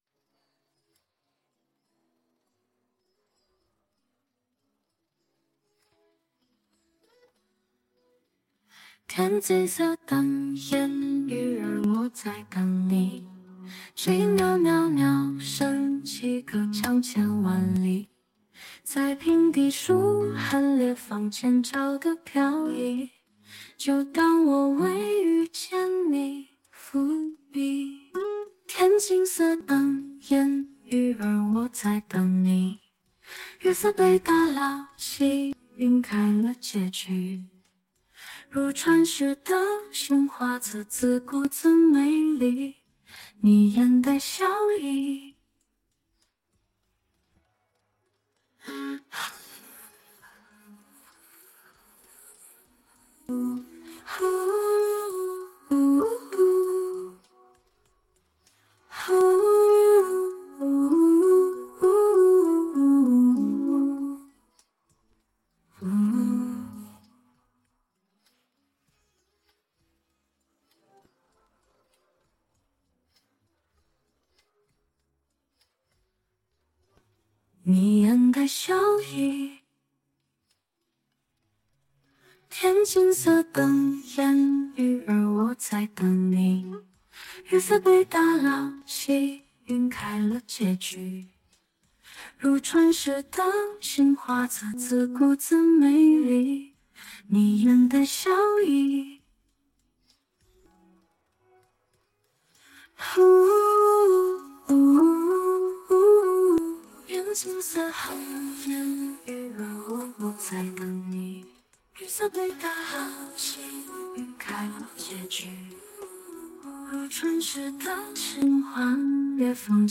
MP3歌曲前景人聲分離
仔細聽了一下，AI 處理分離人聲的效果比預想的好很多，包含過場音樂的「嗚阿喔」都有正確被分離，聽起來不像傳統的軟體會有很多雜音，無論是人聲與音樂的音軌聽起來都很乾淨，如果需要作為後製會是很好的素材來源。
f3cf6ef0-天青色等煙雨-而我在等你_人聲.mp3